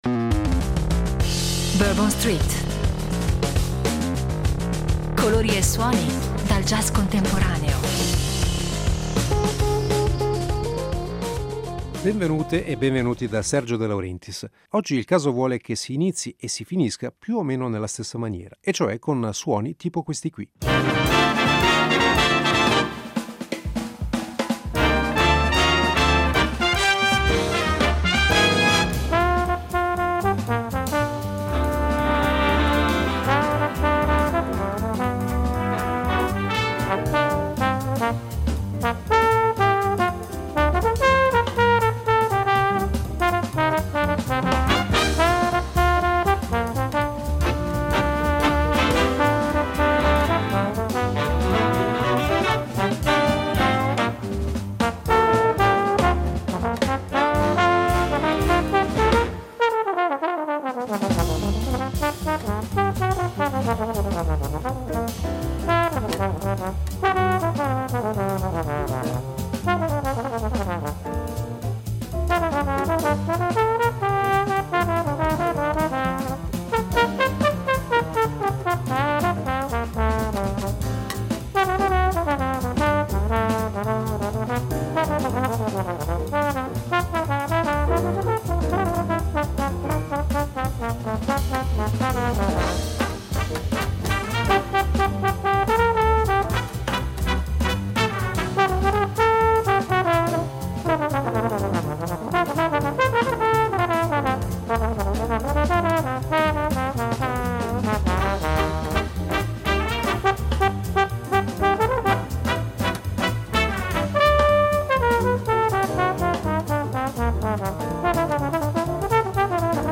Novità dal mondo del jazz